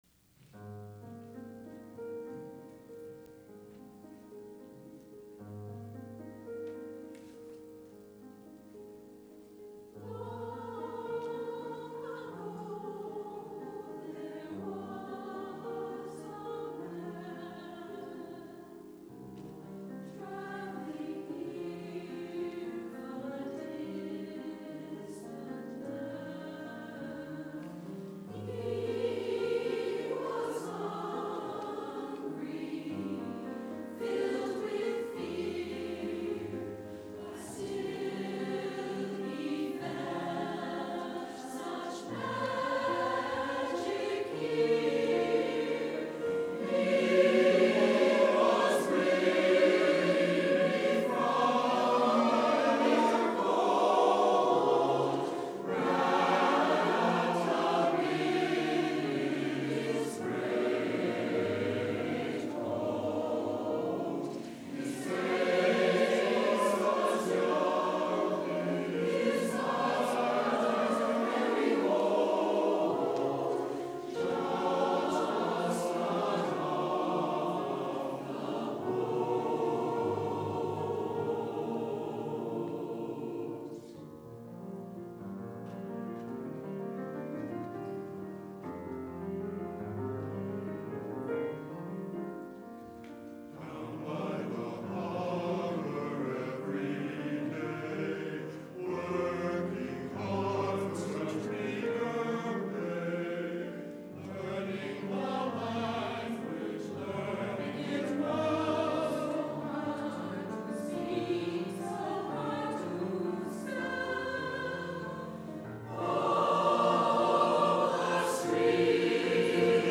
Four-part choral piece
piano